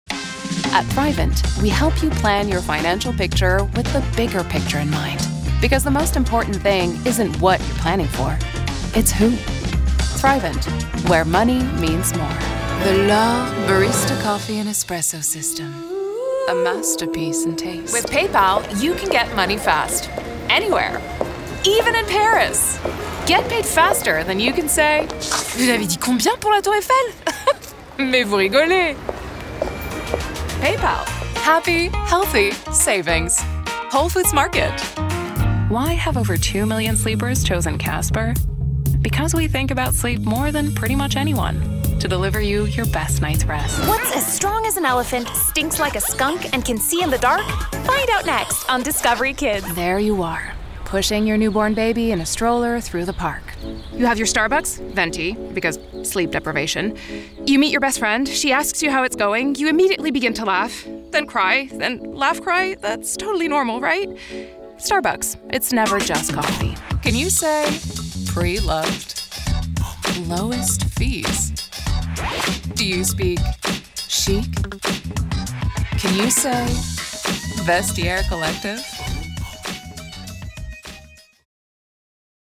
Female
Approachable , Assured , Character , Cheeky , Children , Confident , Conversational , Corporate , Energetic , Engaging , Friendly , Natural , Reassuring , Smooth , Soft , Upbeat , Versatile , Warm , Young
Commercial reel - Spanish.mp3